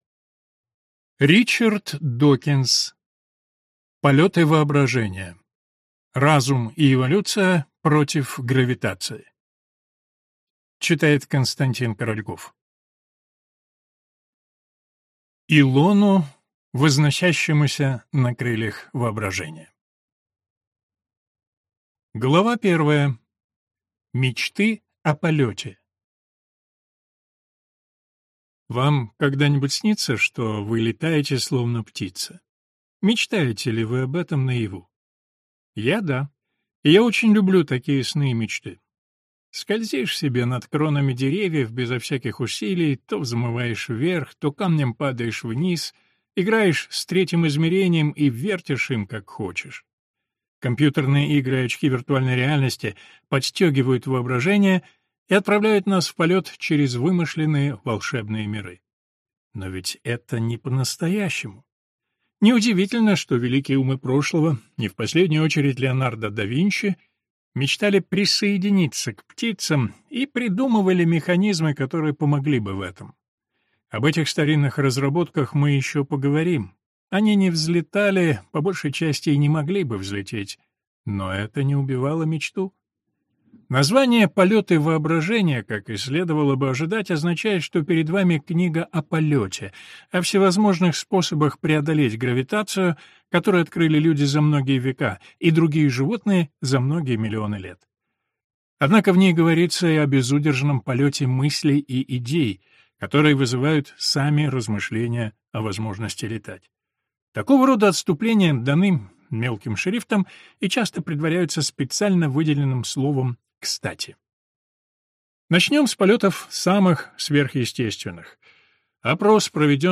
Аудиокнига Полеты воображения. Разум и эволюция против гравитации | Библиотека аудиокниг